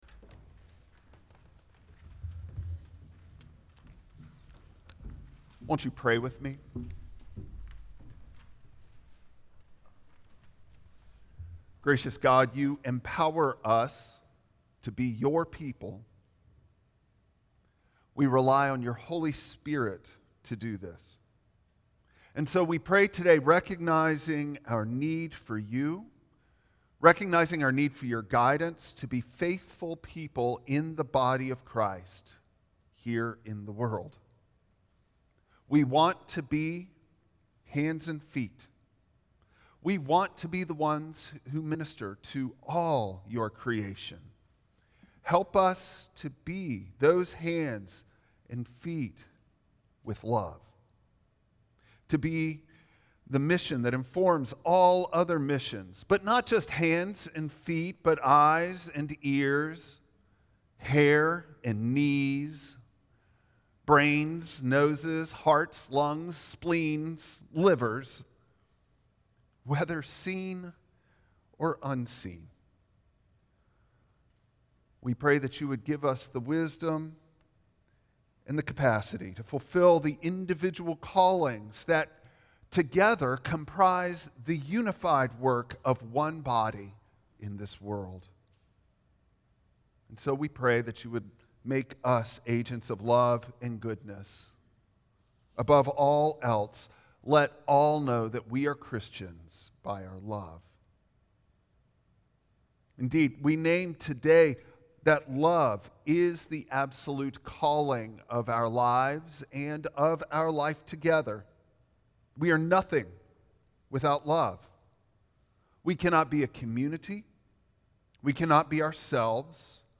Passage: 1 Corinthians 12:12-27 Service Type: Traditional Service